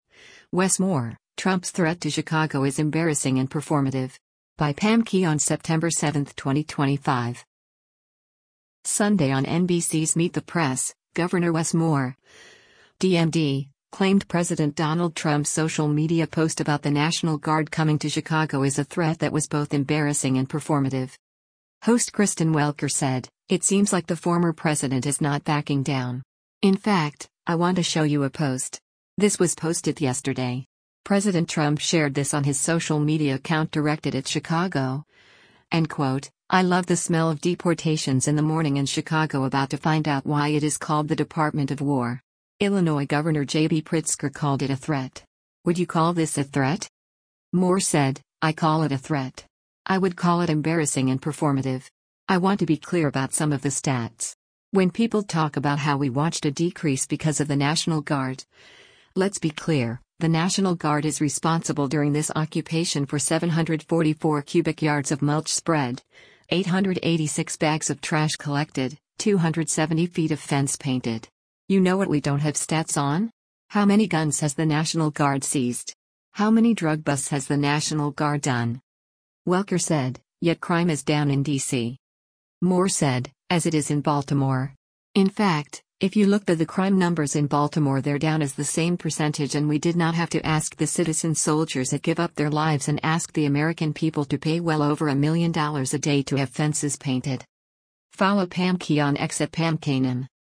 Sunday on NBC’s “Meet the Press,” Gov. Wes Moore (D-MD) claimed President Donald Trump’s social media post about the National Guard coming to Chicago is a “threat” that was both “embarrassing and performative.”